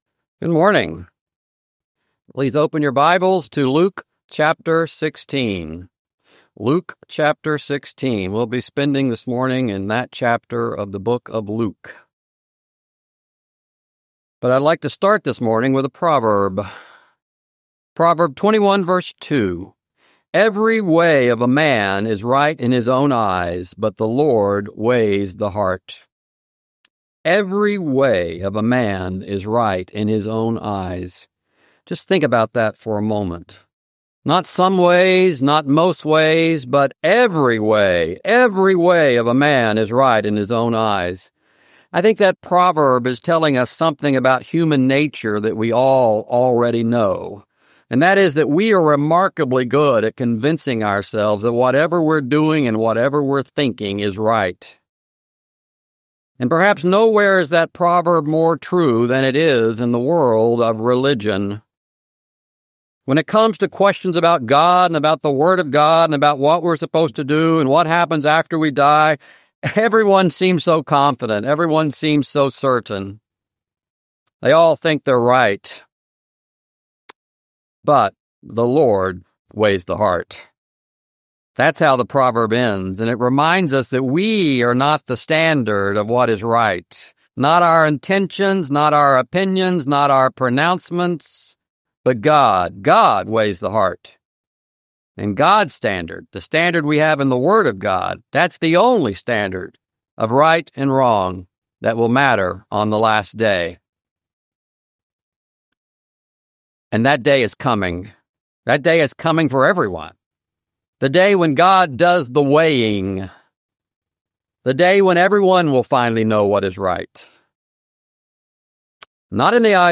Dedicated to the defense and proclamation of the gospel of Christ with sermons, classes, Q&A, and more!